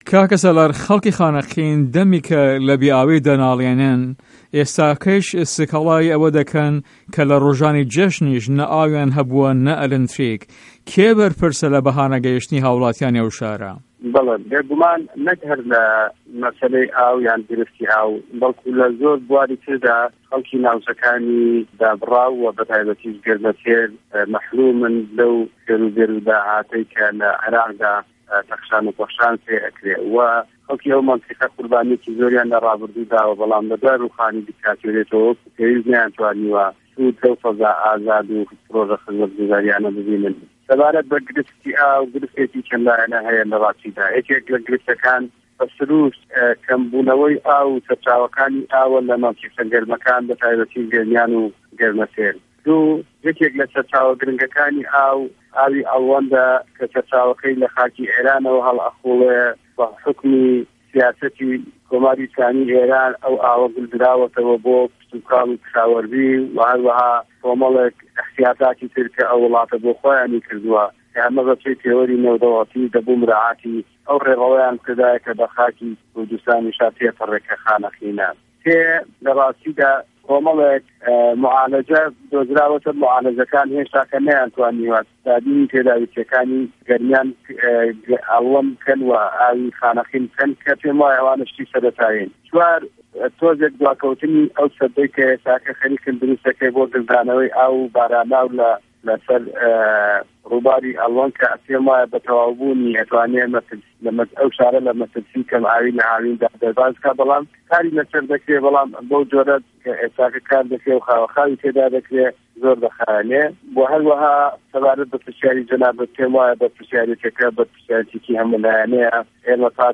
وتو وێژ